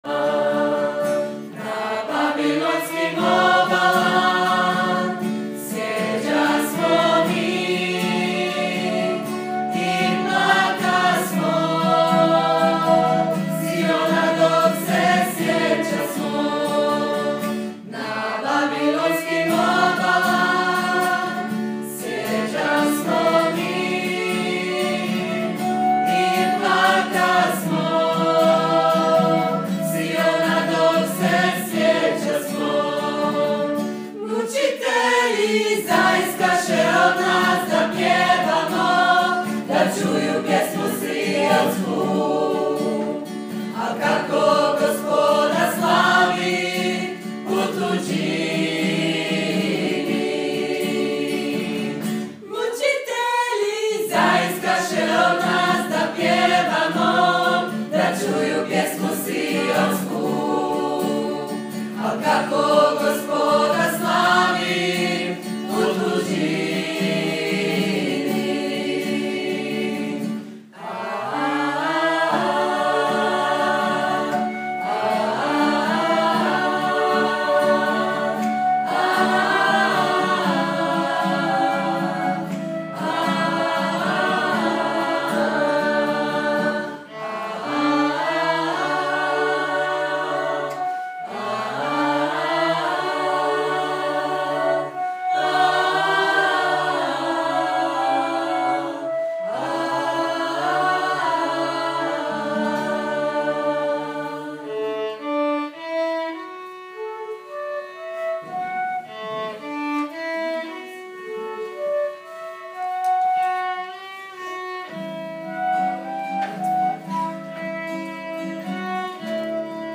CVIJEĆE ZA MARIJU – mp3 dječji zbor župe Krista Kralja (Zagreb)